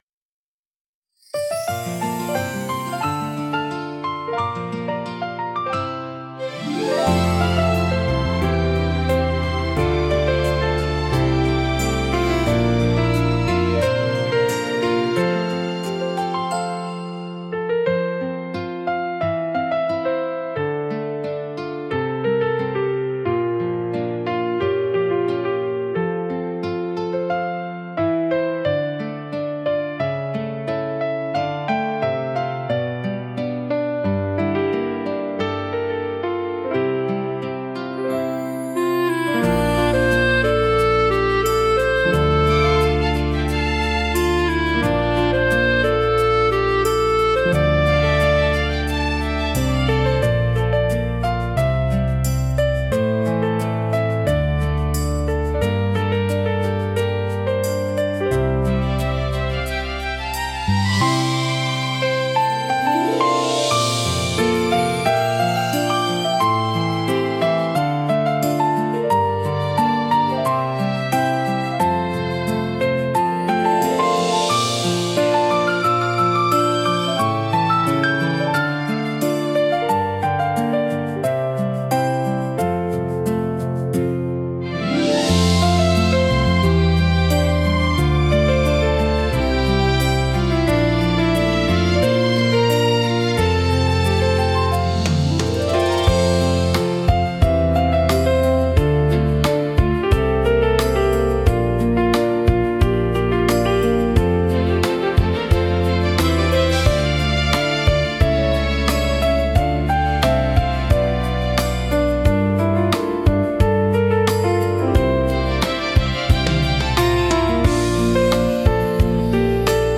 明るさと元気さで、場の雰囲気を一気に盛り上げ、聴く人にポジティブな感情を喚起します。